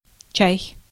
Ääntäminen
IPA : /tiː/ US : IPA : [tiː] UK